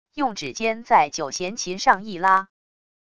用指尖在九弦琴上一拉wav音频